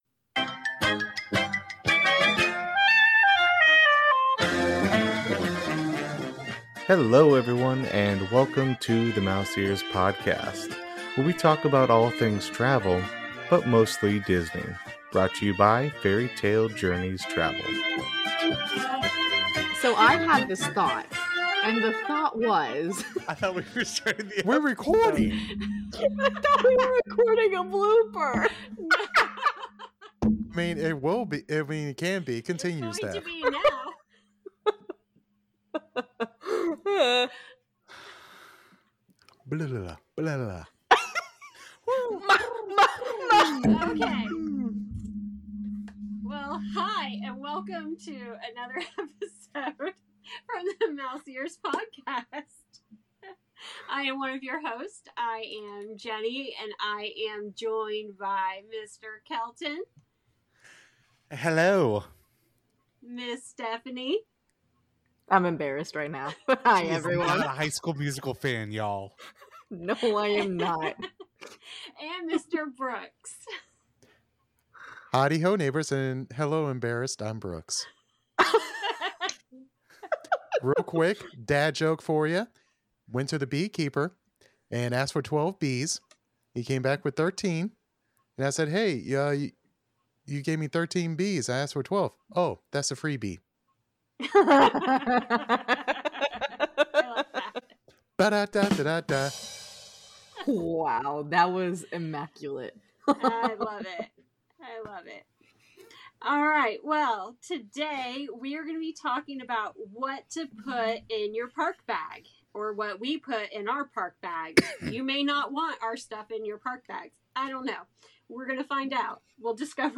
Join these 4 Disney junkies as they reveal what they bring to the parks to make sure they are ready for anything when vacationing.